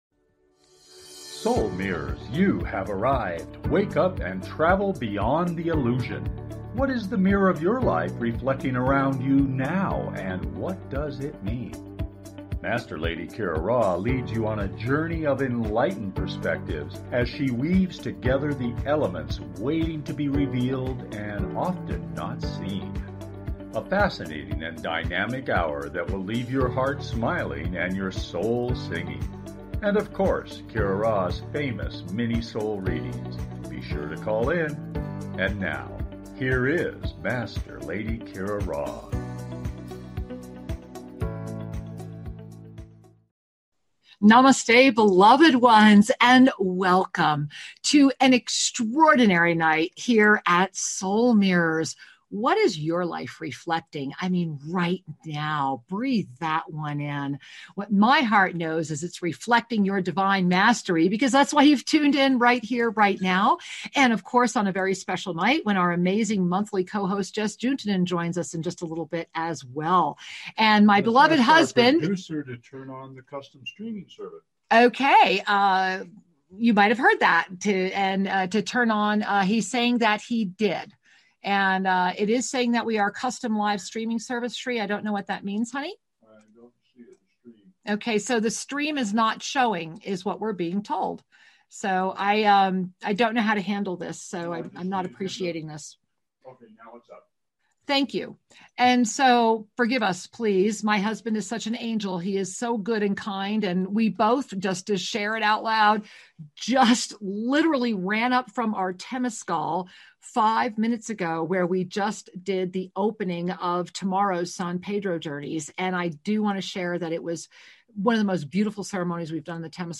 Talk Show
Lively, entertaining, and refreshingly authentic, the hour goes quickly!